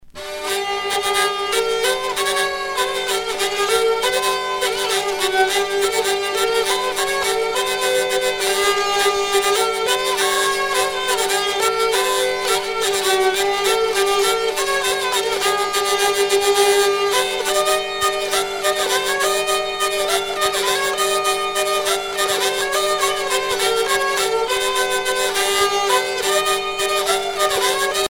danse : valse
Sonneurs de vielle traditionnels en Bretagne
Pièce musicale éditée